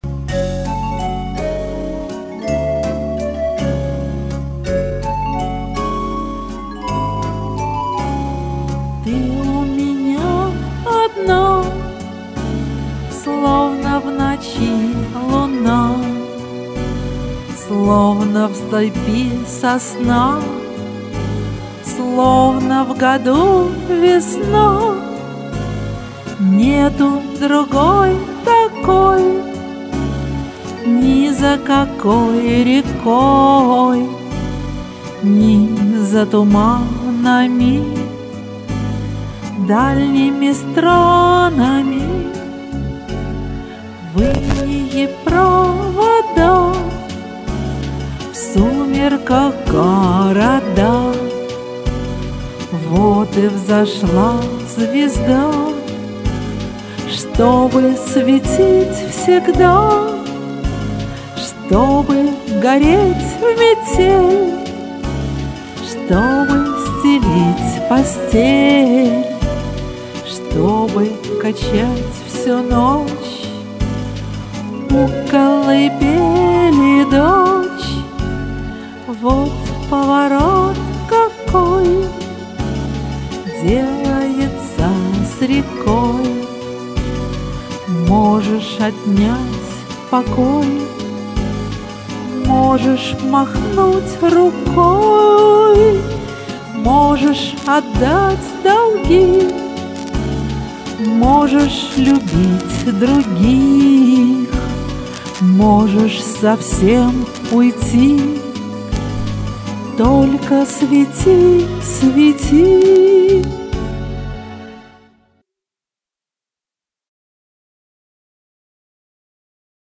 С точки зрения следования нотам все спето очень правильно.